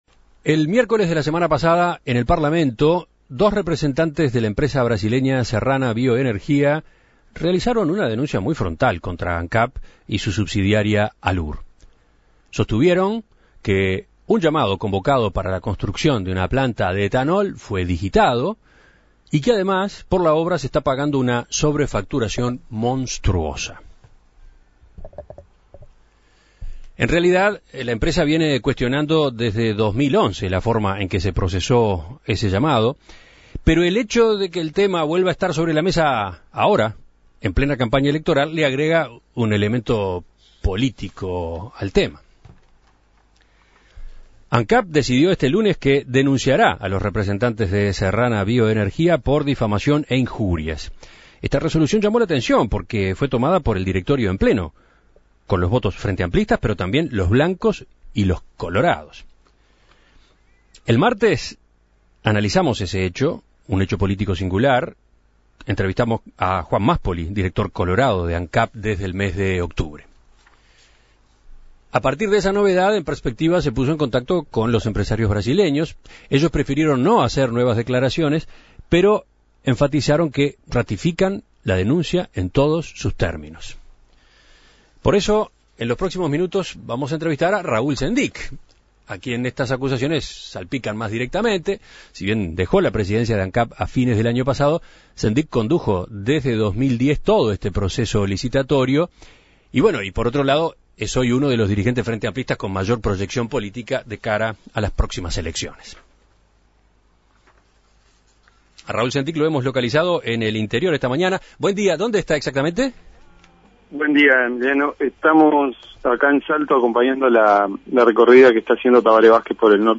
En Perspectiva dialogó con Sendic, quien a propósito de esto decidió presentar una denuncia por injuria y agravio.